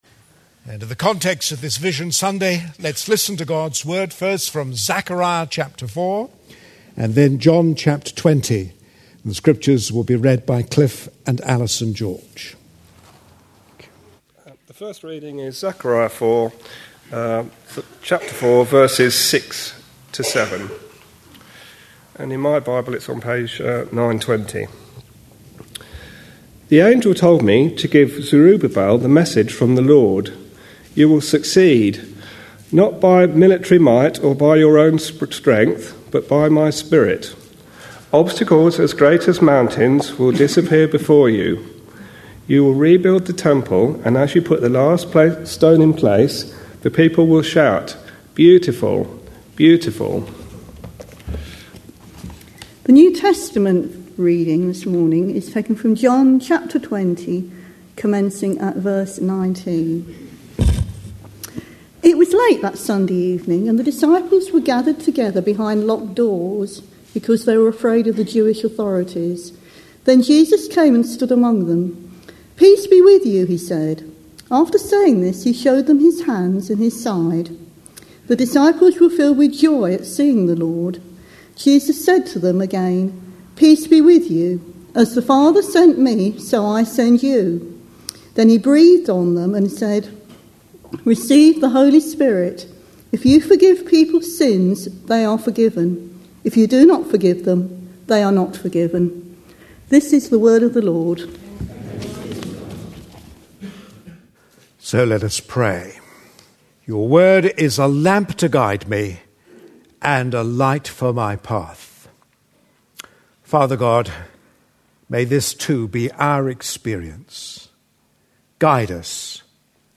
A sermon preached on 9th January, 2011, as part of our A Passion For.... series.
John 20:19-23 Listen online Read a transcript Details Readings are Zechariah 4:6-7 and John 20:19-23. Slight interference on sound due to radio microphone cutting out.